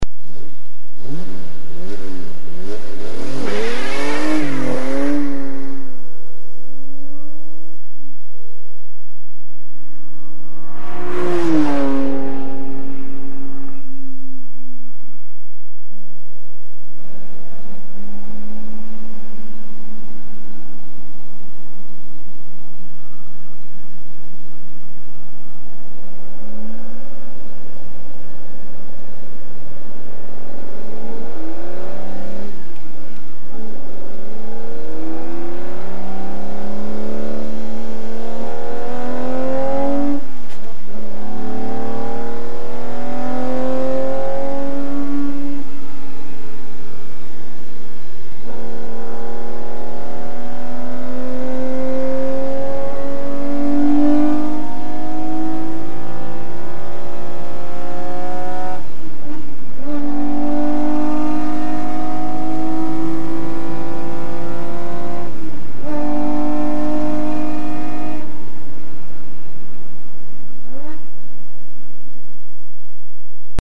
A jármű hangja általában hármas felosztásban hallható:
indulás, elhaladás, kocsiban ülve.
Ferrari_250gto.mp3